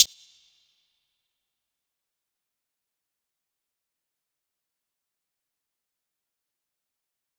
Closed Hats
DMV3_Hi Hat 4.wav